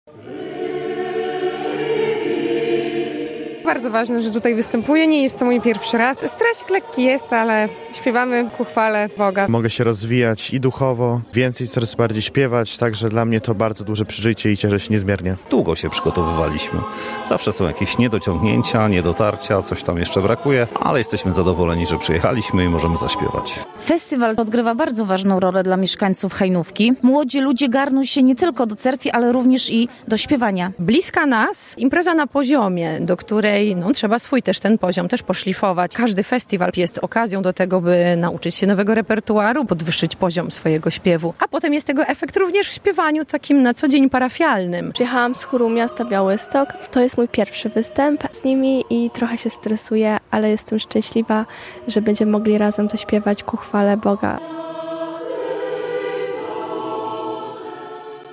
W soborze św. Trójcy w Hajnówce można było usłyszeć muzykę cerkiewną współczesnych kompozytorów, ale też melodie monasterskie i pieśni paraliturgiczne.